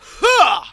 VOX SHORTS-1 0002.wav